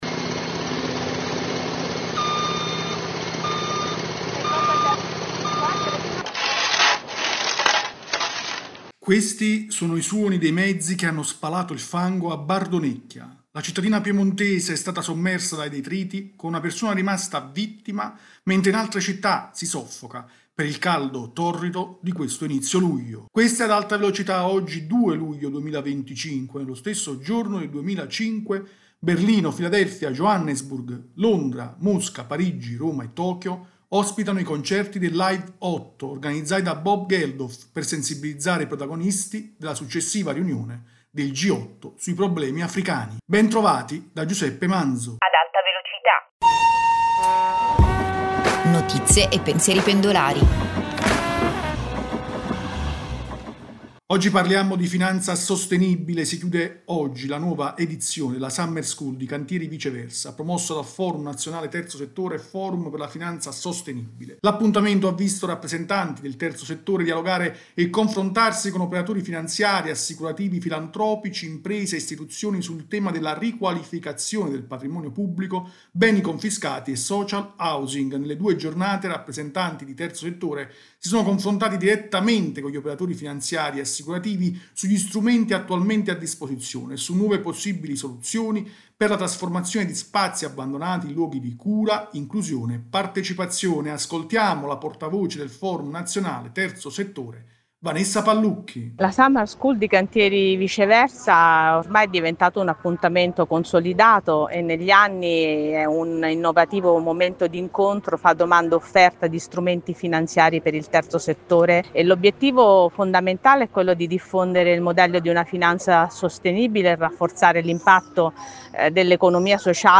[Intro: Questi sono i suoni dei mezzi che hanno spalato il fango a Bardonecchia: la cittadina piemontese è stata sommersa dai detriti con una persona rimasta vittima mentre in altre città si soffoca per il caldo torrido di questo inizio luglio.
rubrica quotidiana